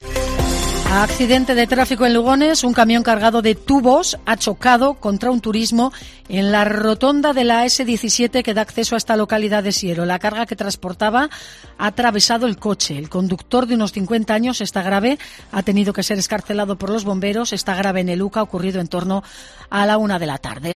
Así hemos contado en COPE el accidente de tráfico con un herido grave en Lugones